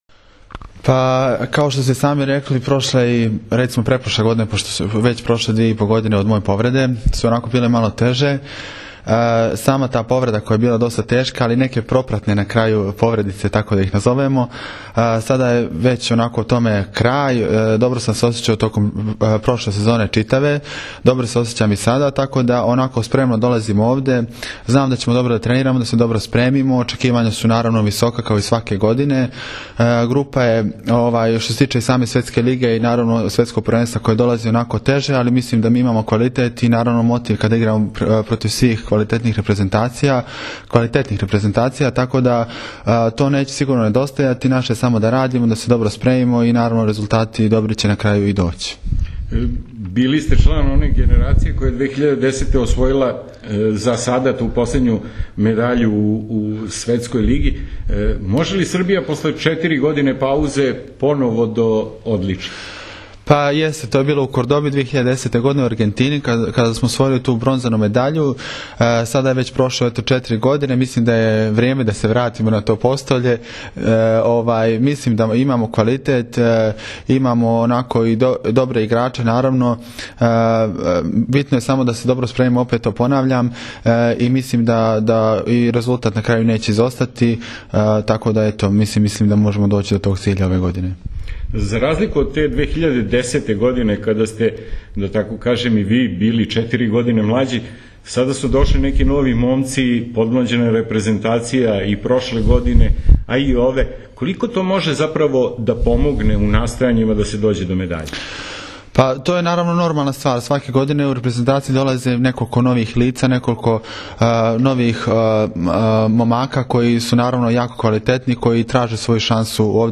Starović je istakao da je publika u Srbiji “sedmi igrač” naše reprezentacije i da je uz njenu podršku moguće savladati svakog rivala. INTERVJU SA SAŠOM STAROVIĆEM